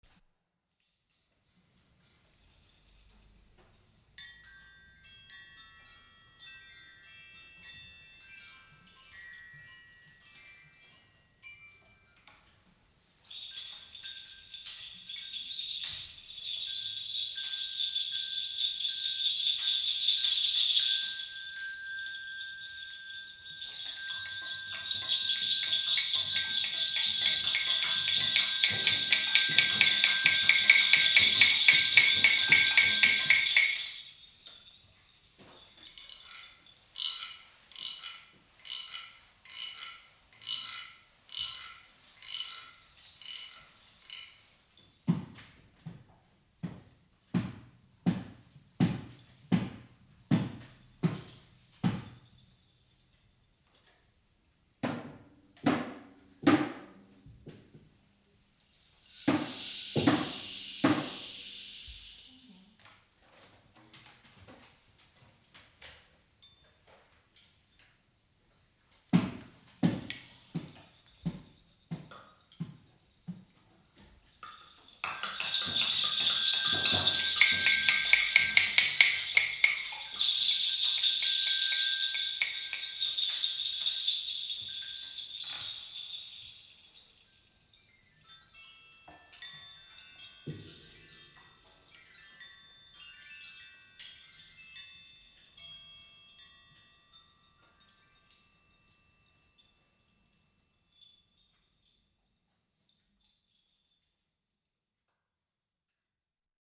1 Verschneite Mondlandschaft
2 Schlitten mit Glöckchen
3 Hufgetrappel
4 Pferdeschnauben
5 Schritte in dicken Stiefeln
6 Türklopfen
8 Sich entfernende Schritte
Aufnahme: Verklanglichung der Nikolausgeschichte durch eine 4. Klasse (ohne Text)
Klanggeschichte Nikolaus 4. Klasse 1.mp3